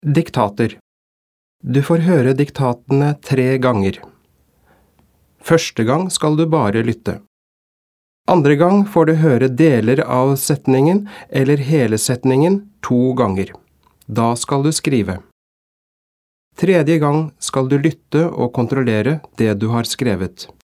Diktat leksjon 1
Introduksjon.mp3